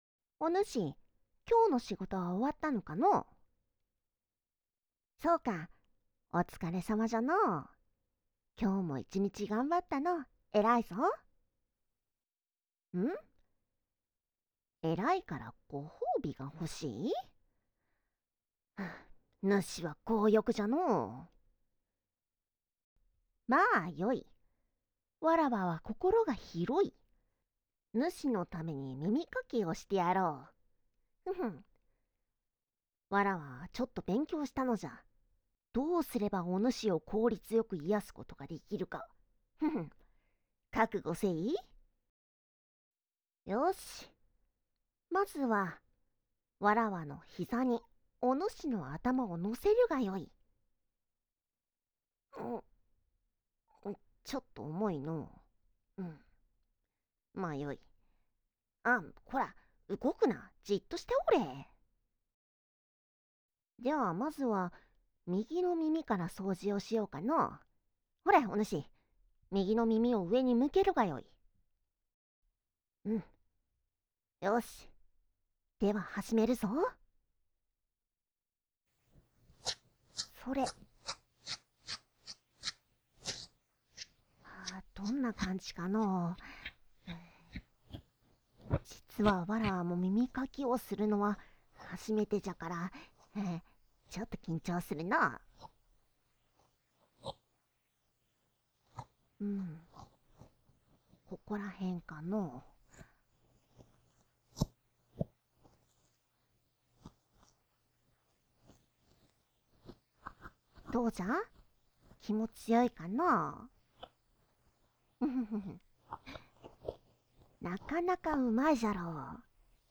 纯爱/甜蜜 温馨 年龄差 萝莉 掏耳 评分：4.57 发布：2023-03-02 のじゃロリ神様が耳かきをしてやるのじゃ♪ ご挨拶.txt 预览 下载 のじゃロリ神様が耳かきをしてやるのじゃ♪.wav 预览 下载